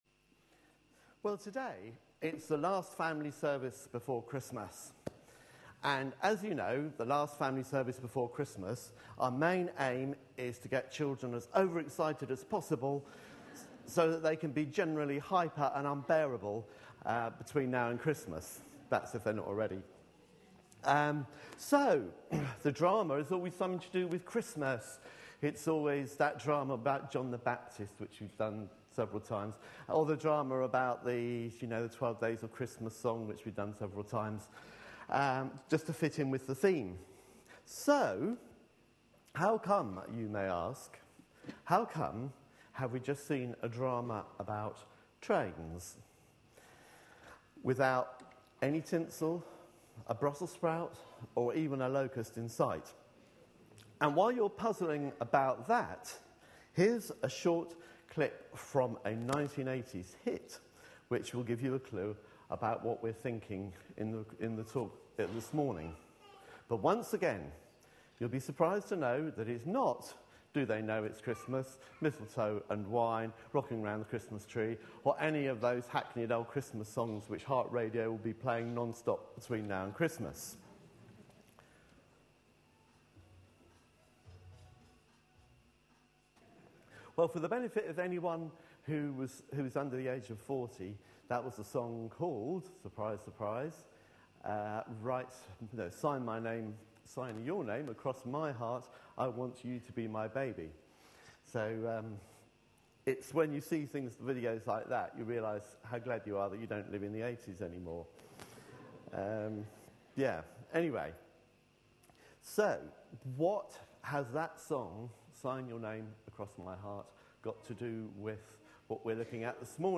A sermon preached on 18th November, 2012.
Jeremiah 31:31-34 Listen online Details Reading is Jeremiah 31:31-34 (about 10 minutes in from the start). This is from a family/all-age service, and just before the talk there was a performance of a comic sketch "All Change!", featuring passengers' actions on an Underground train.